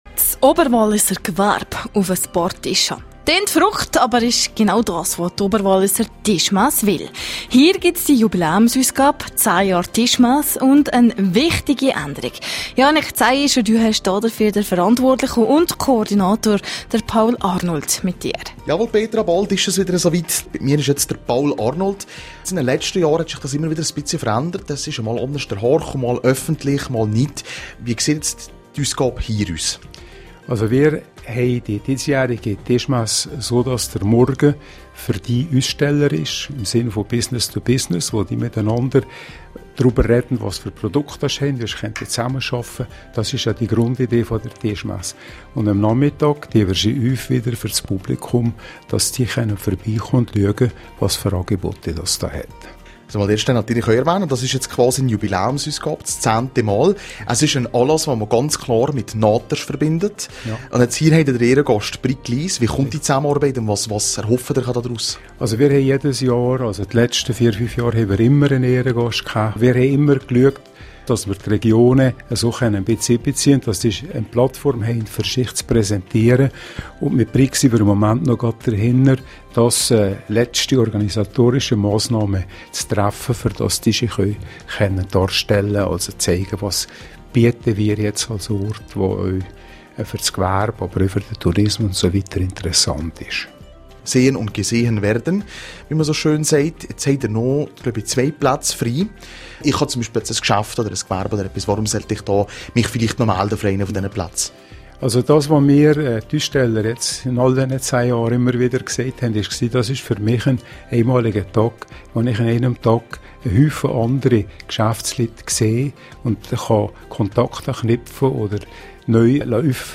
17:00 Uhr Nachrichten (4.17MB)